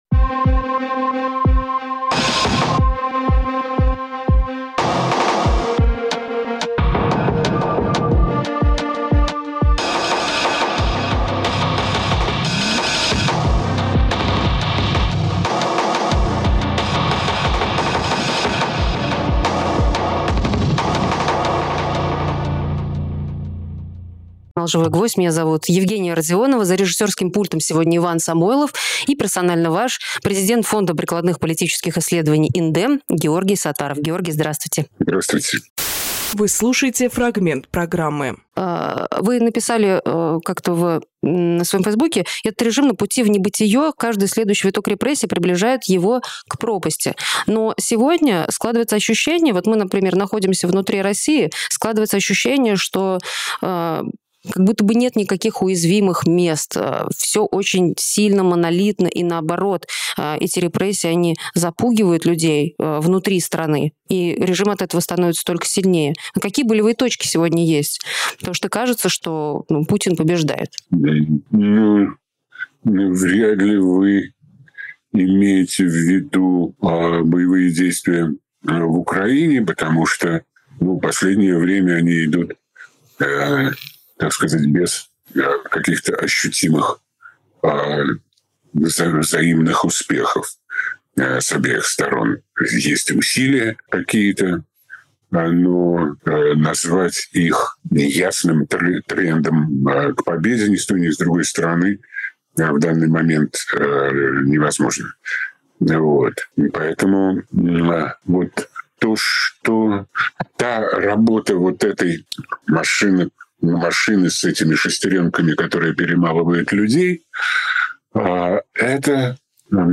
Георгий Сатаровполитолог
Фрагмент эфира от 22.07